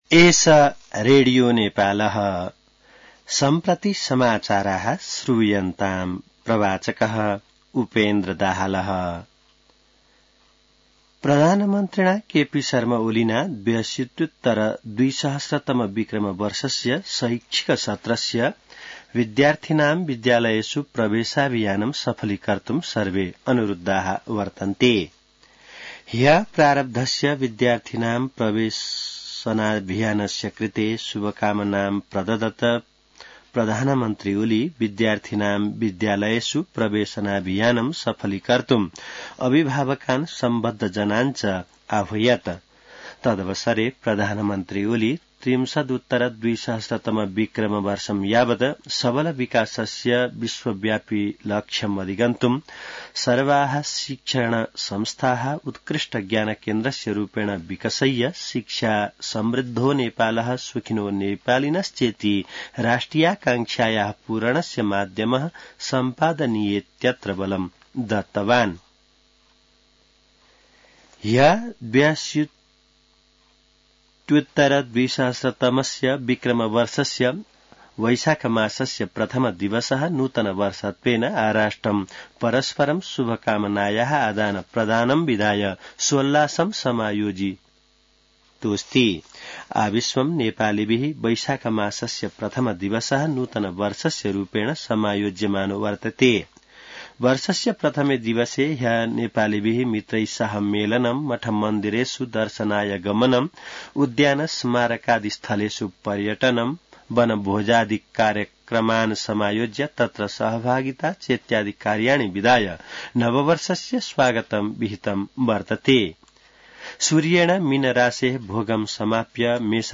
An online outlet of Nepal's national radio broadcaster
संस्कृत समाचार : २ वैशाख , २०८२